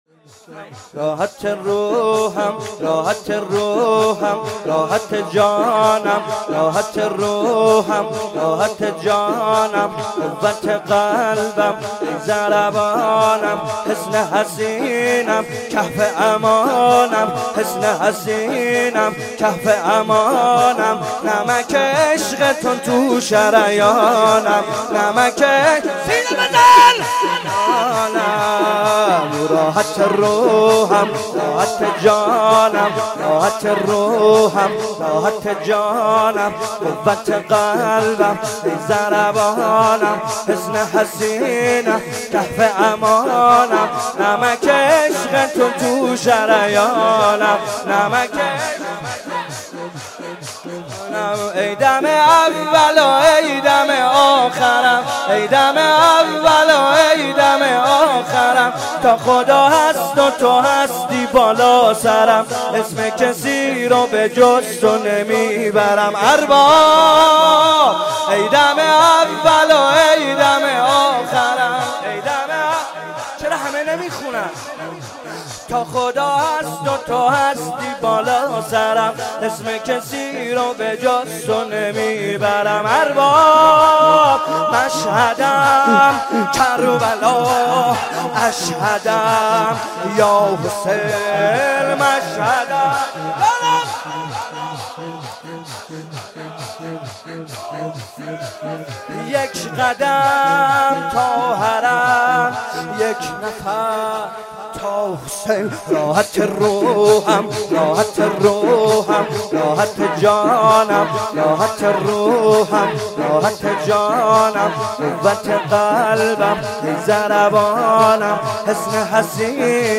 شور، شهادت حضرت زهرا(س)